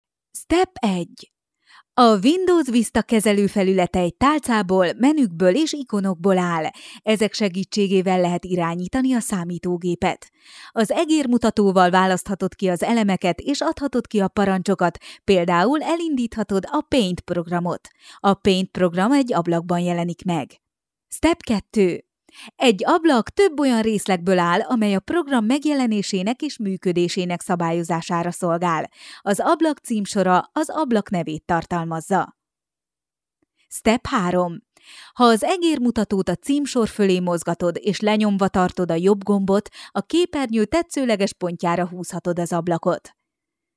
Sprecherin ungarisch für TV / Rundfunk / Industrie.
Kein Dialekt
Sprechprobe: eLearning (Muttersprache):
Professionell voice over artist from Hungary.